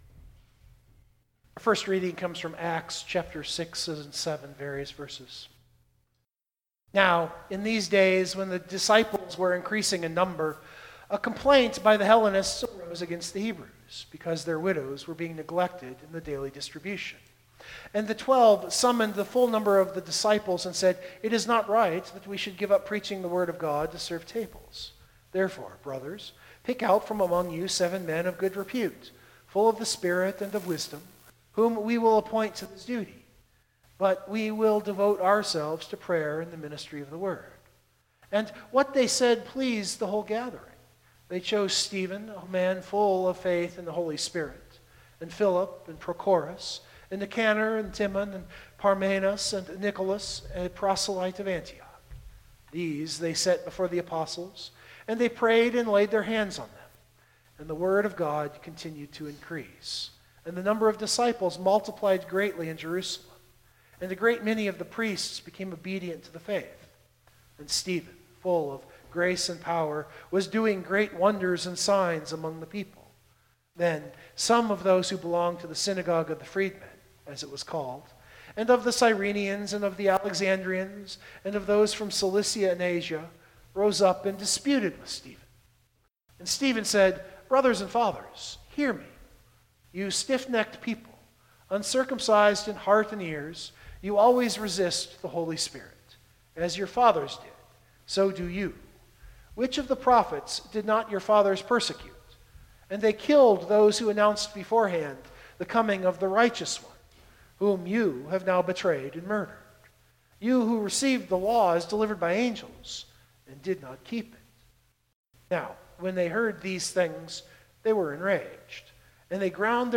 032226 Sermon Download Biblical Text: John 11:1-48 The introduction on a non-essential point is too long.